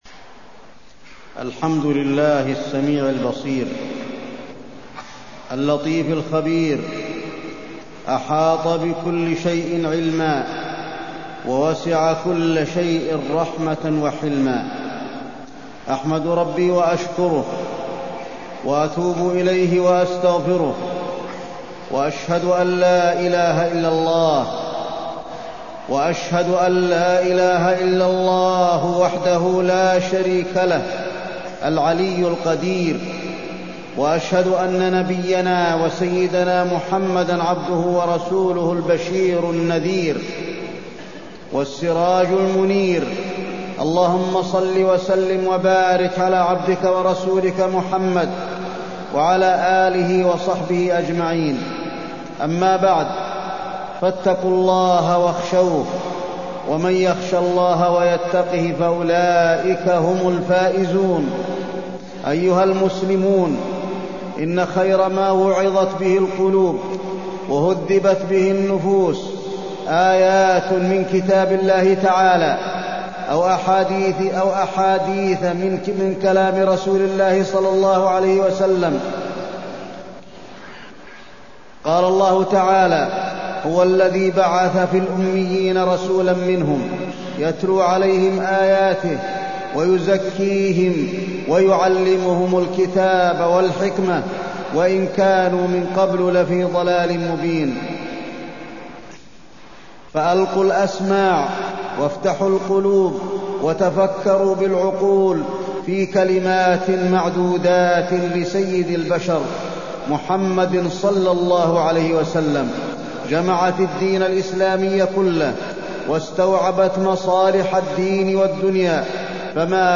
تاريخ النشر ١٨ جمادى الأولى ١٤٢٤ هـ المكان: المسجد النبوي الشيخ: فضيلة الشيخ د. علي بن عبدالرحمن الحذيفي فضيلة الشيخ د. علي بن عبدالرحمن الحذيفي النصيحة